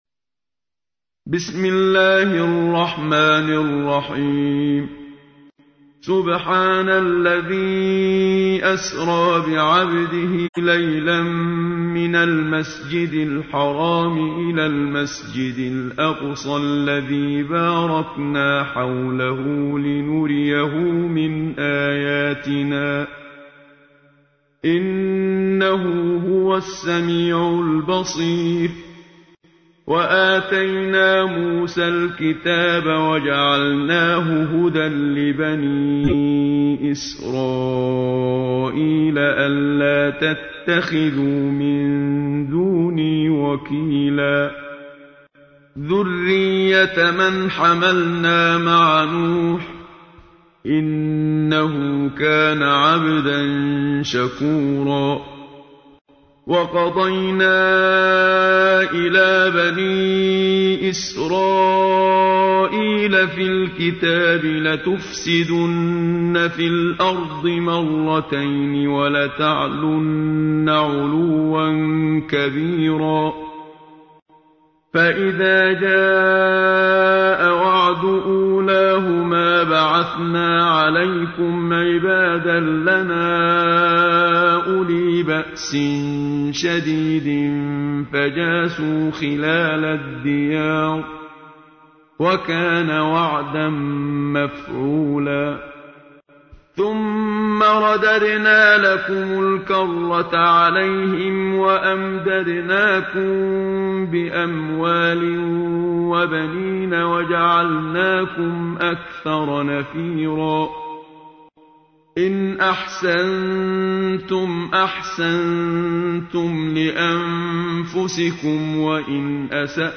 تحميل : 17. سورة الإسراء / القارئ محمد صديق المنشاوي / القرآن الكريم / موقع يا حسين